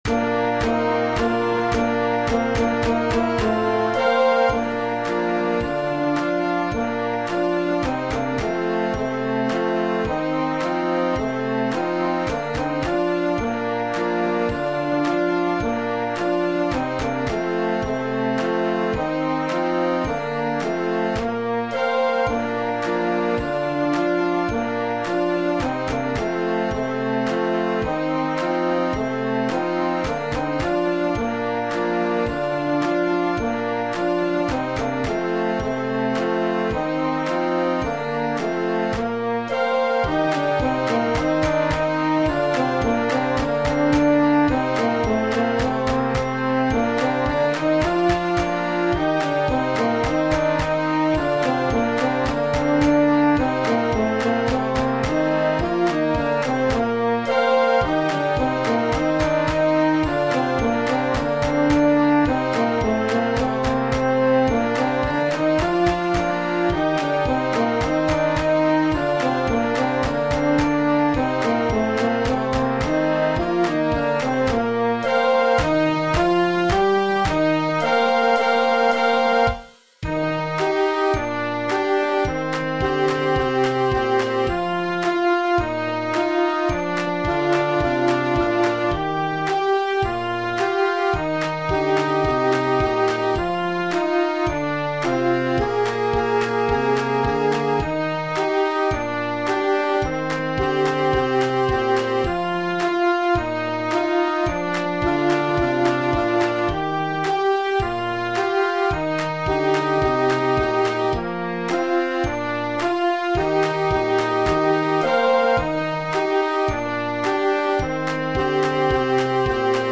Beginning Band
(Concert March)